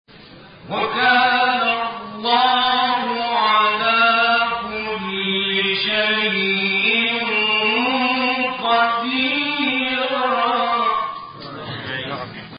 گروه شبکه اجتماعی: مقاطعی صوتی از راغب مصطفی غلوش که در مقام حسینی اجرا شده است، می‌شنوید.
این مقاطع که در مقام حسینی اجرا شده‌اند، در زیر ارائه می‌شوند.